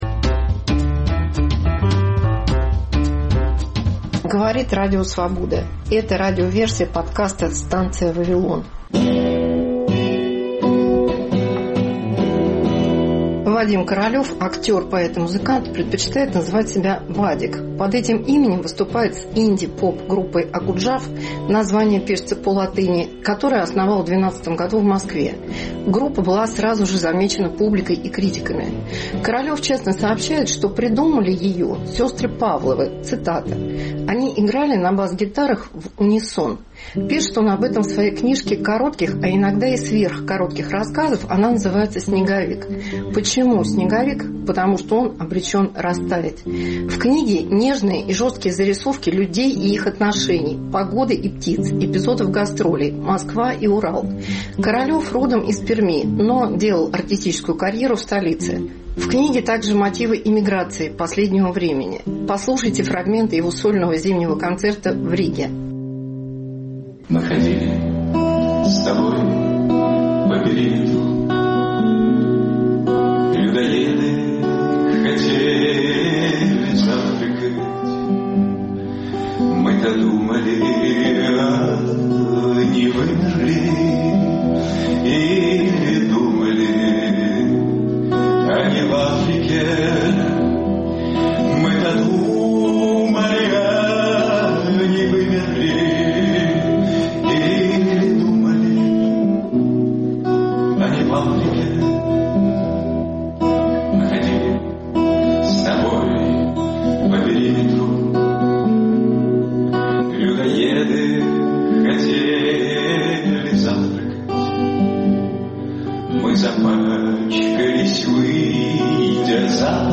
Новогодний концерт